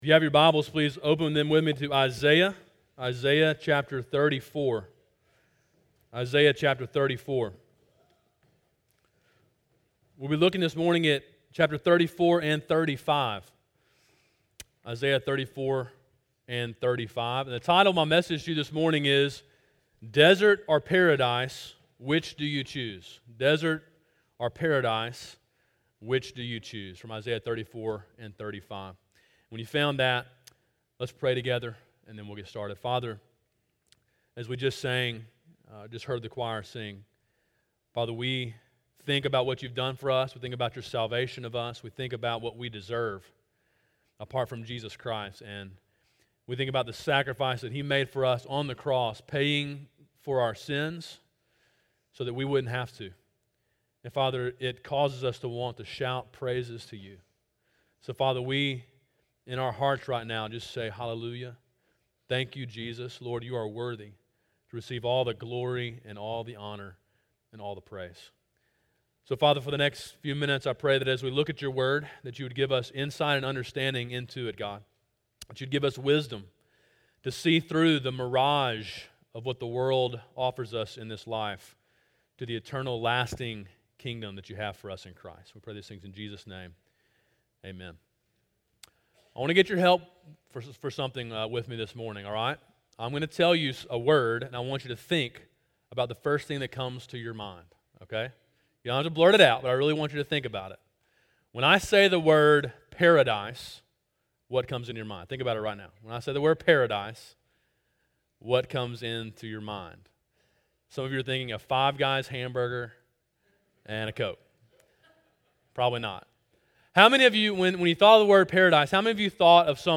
sermon5-21-17.mp3